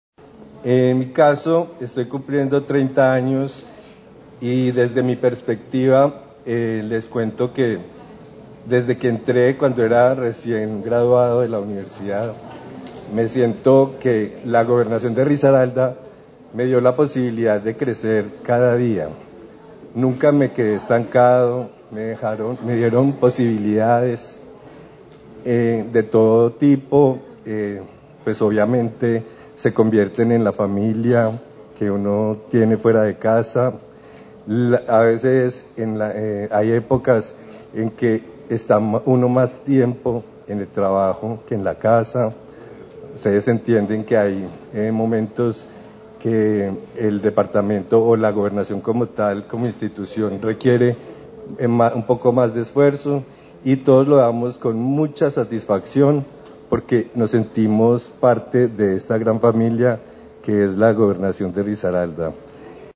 Para exaltar los logros y méritos de quienes se destacan por su trayectoria en administración departamental, la Gobernación de Risaralda lideró el evento ‘Tiempos de Servicios’.